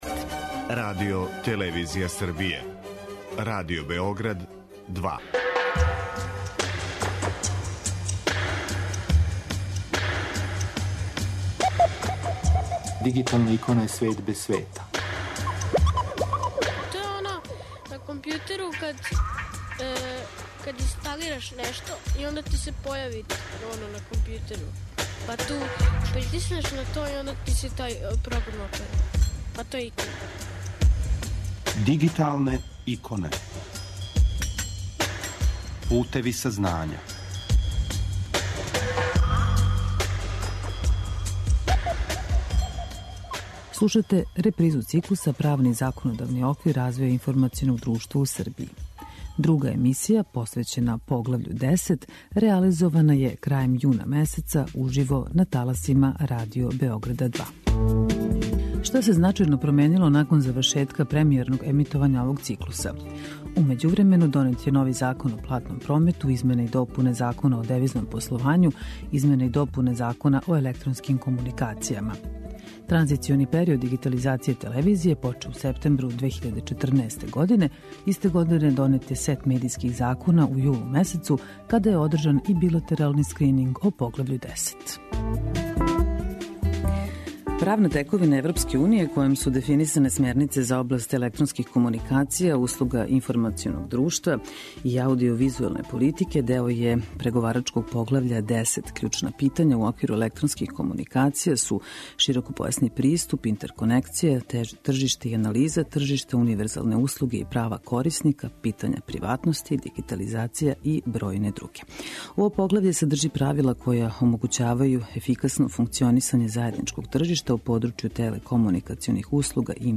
Слушате репризу циклуса 'Правни и законодавни оквир развоја информационог друштва у Србији'. Друга емисија, посвећена Поглављу 10, реализована је крајем јуна месеца, уживо, на таласима Радио Београда 2.